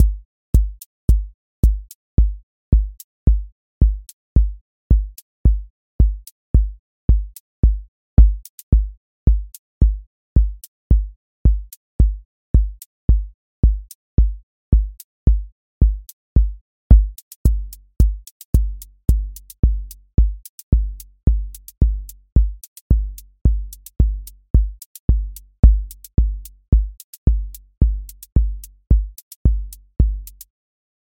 Four on Floor QA Listening Test house Template
voice_kick_808 voice_hat_rimshot voice_sub_pulse